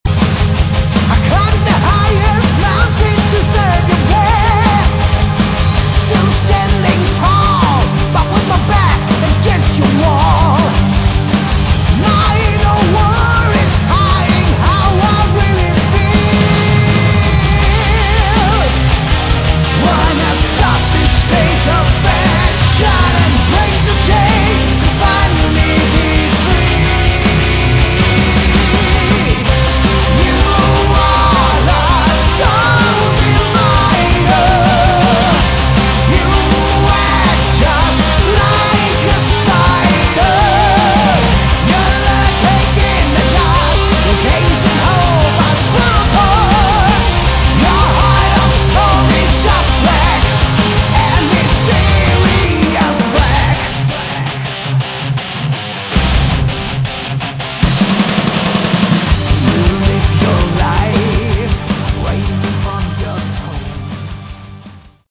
Category: Melodic Metal
vocals
guitar
bass
drums